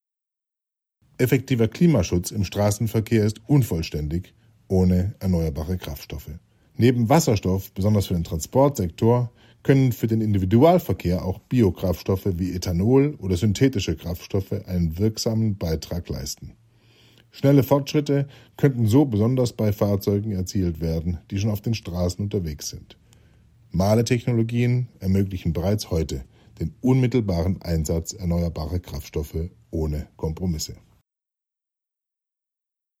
Soundbite